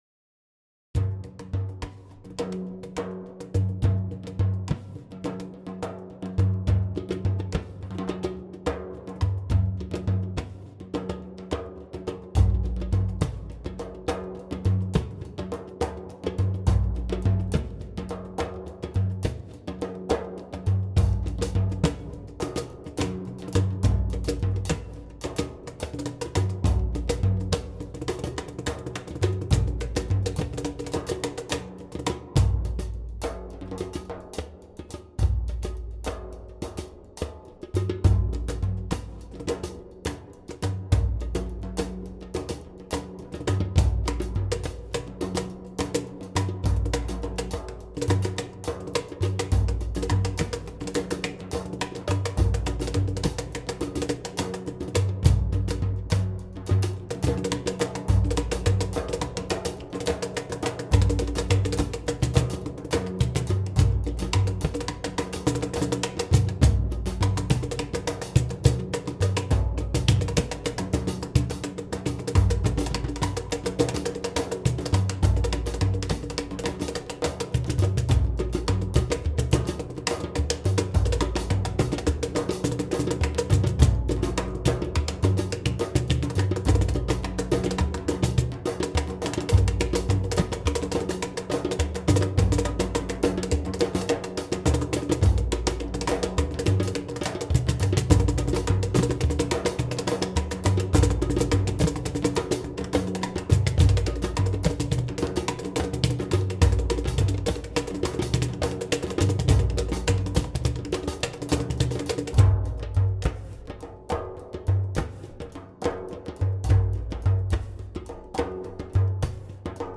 This little ditty moves from a 5/4 to a big 5 waltz.
This tune has frame drum, djembe, hi hat, snare, cajon, and djun djun.